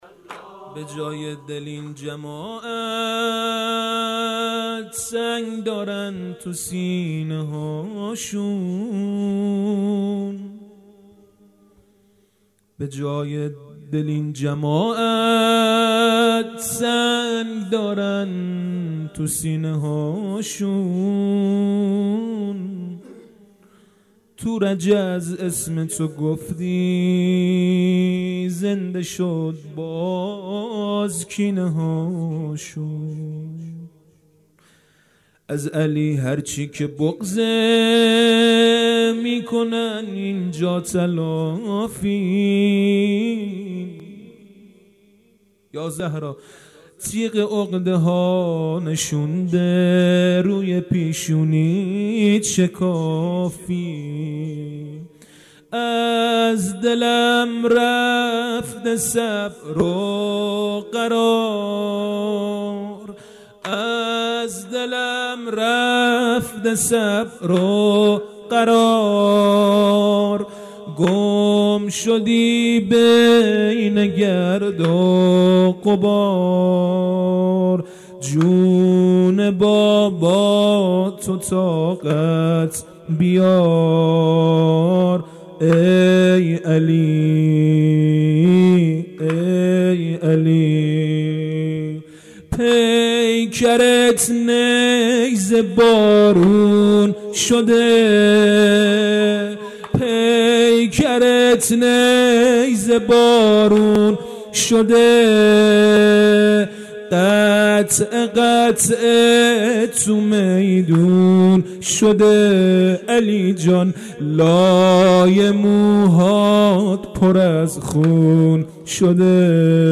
خیمه گاه - هیئت حضرت رقیه س (نازی آباد) - شب هشتم مداحی
محرم سال 1398